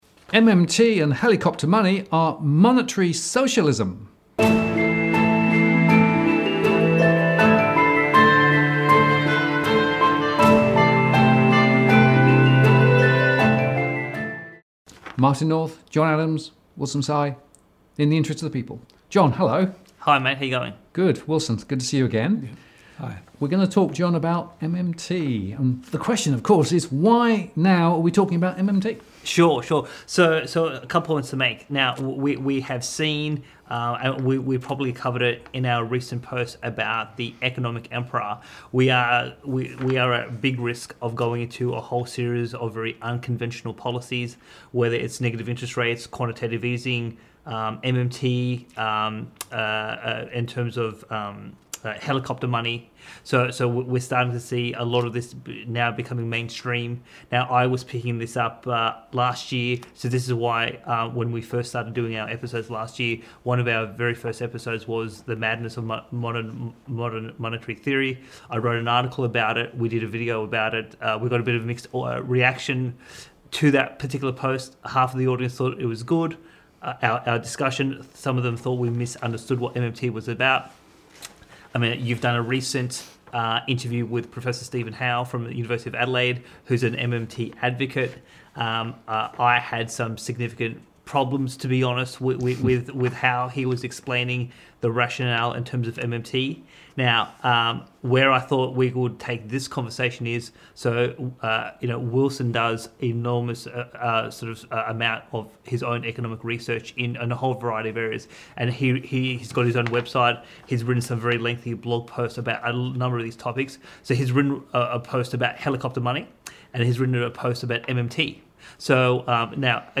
A Conversation With Steve Keen: Part 2 – From Central Bankers To MMT [Podcast]